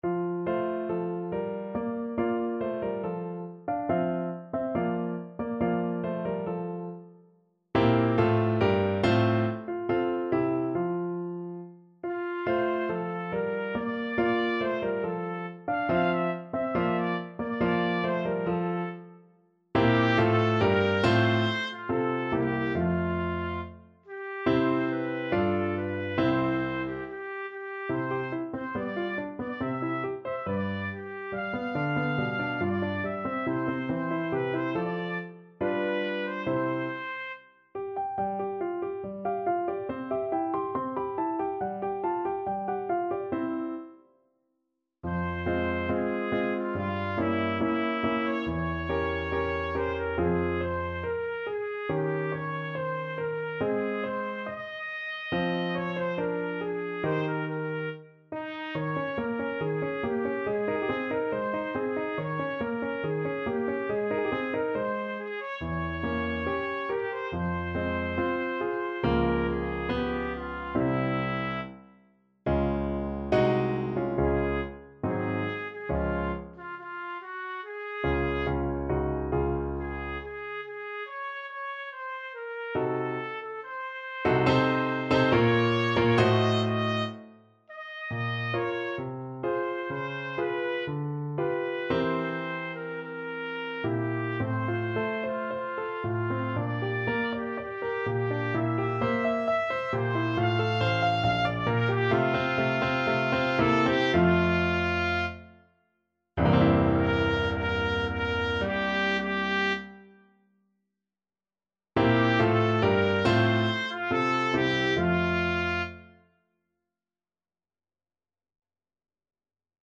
Trumpet version
= 70 Allegretto
2/4 (View more 2/4 Music)
Classical (View more Classical Trumpet Music)